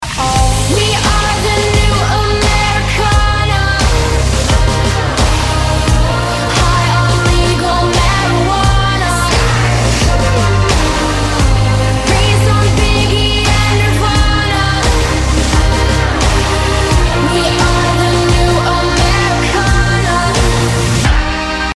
• Качество: 256, Stereo
поп
женский вокал
alternative